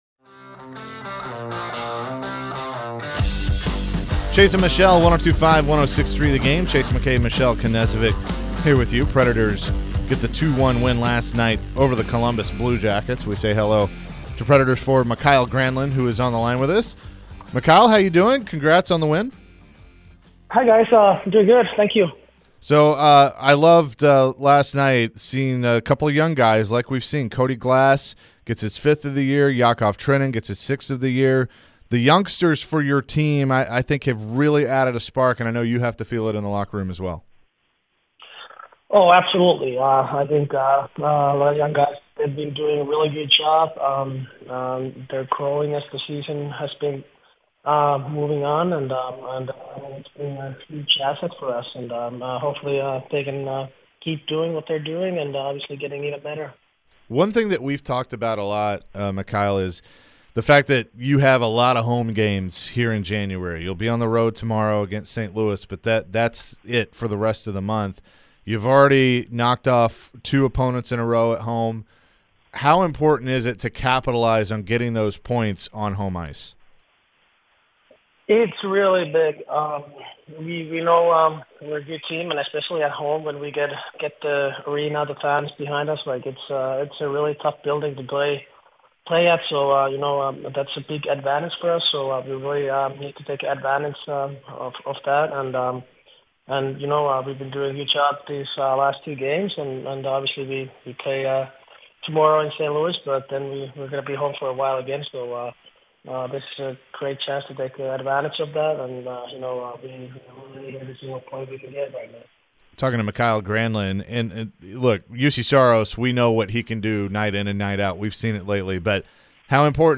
Mikael Granlund interview (1-18-23)